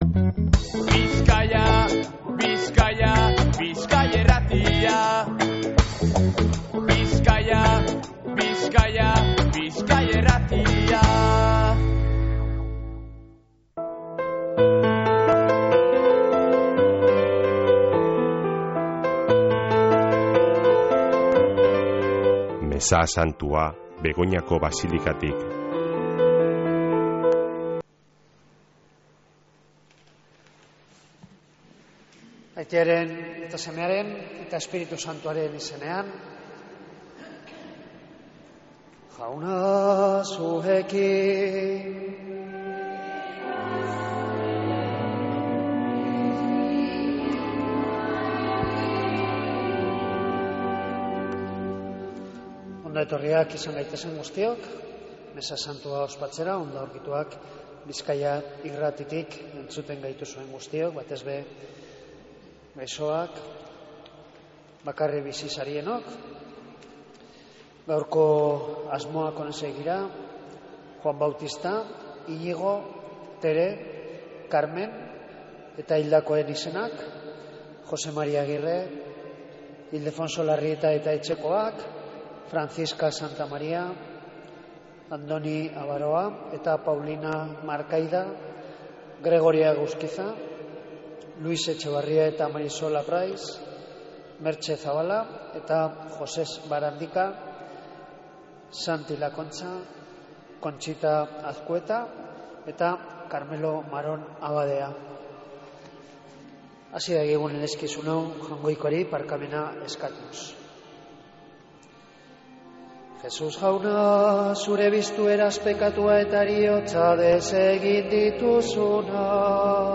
Mezea Begoñako basilikatik | Bizkaia Irratia
Mezea (25-05-16)